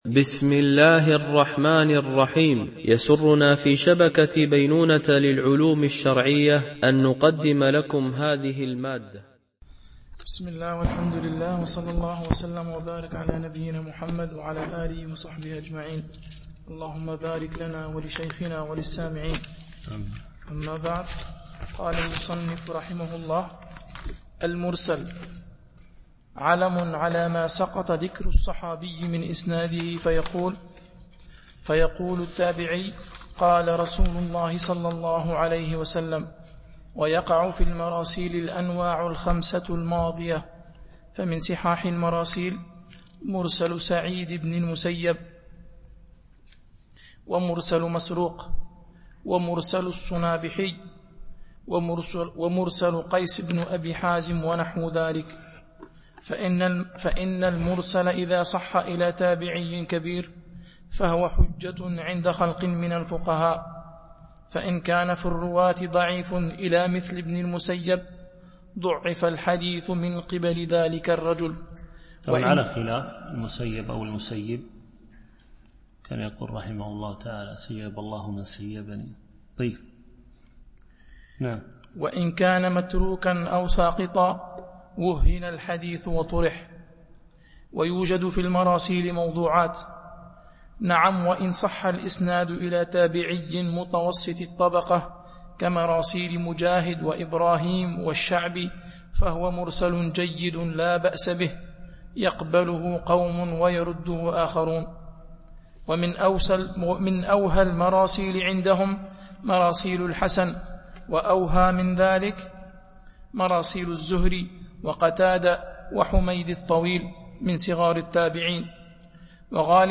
شرح الموقظة في علم مصطلح الحديث ـ الدرس 6 (الحديث المرسل والمعضل والمنقطع)